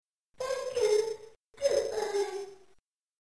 As Rick picks her up, she says something that sounds like
"Thank you..." and then disappears into ash.